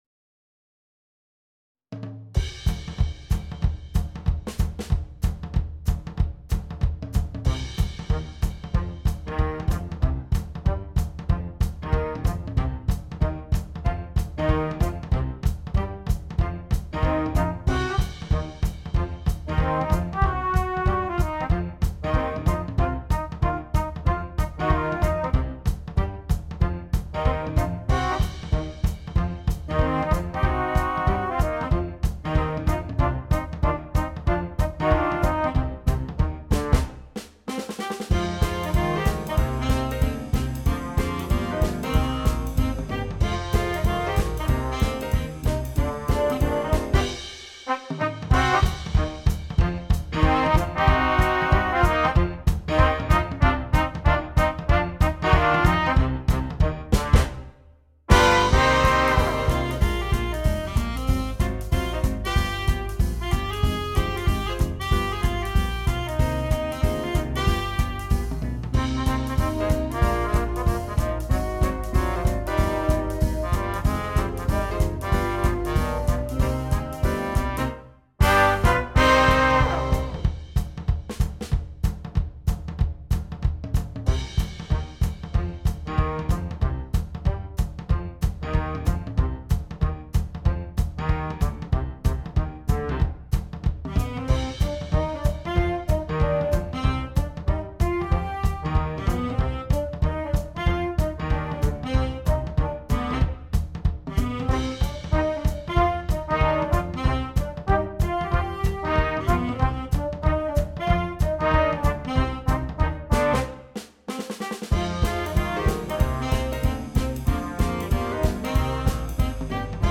Jazz Band
Difficulty: Easy Order Code
This jump swing chart